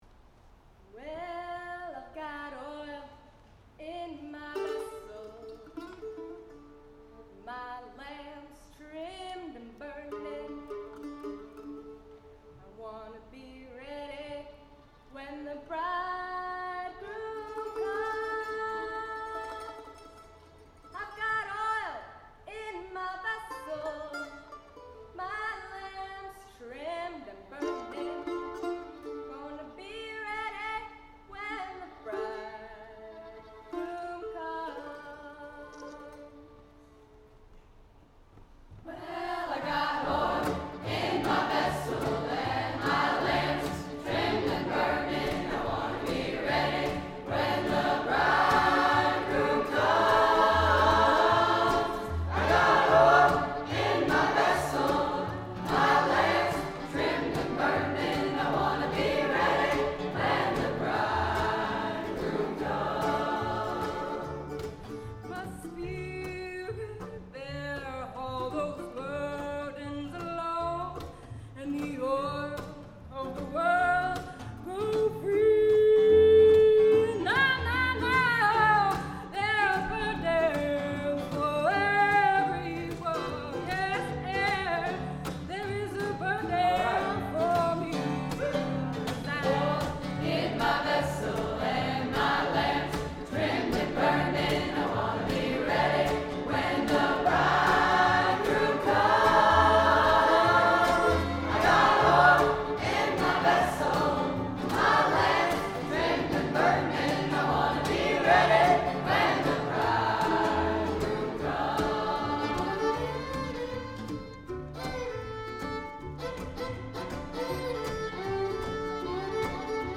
Chamber, Choral & Orchestral Music
Chorus